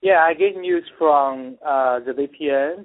THIS CHINESE MAN WHO WHO LIVES IN CHINA SAYS HE USES A VPN (VIRTUAL PRIVATE NETWORK) TO CIRCUMVENT GOVERNMENT INTERNET BLOCKS.
MAN-IN-China-I-use-a-VPN.mp3